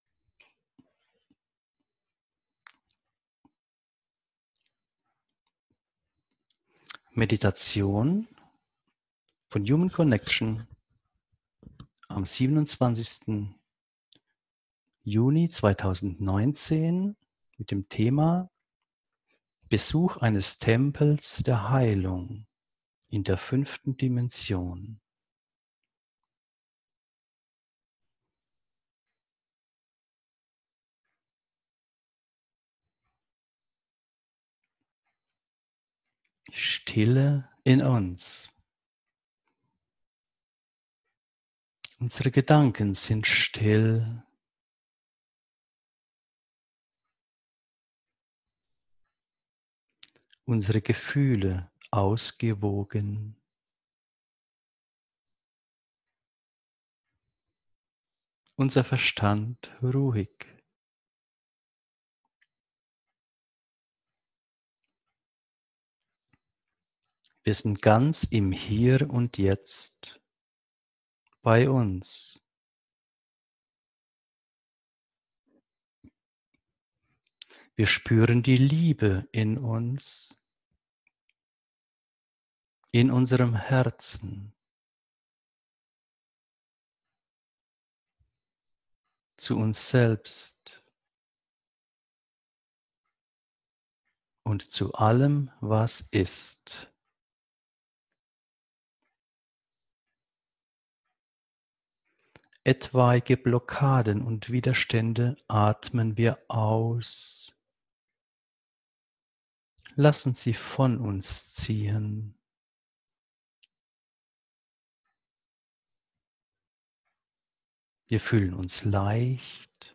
Geführte Meditation